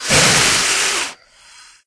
c_wserpent_atk2.wav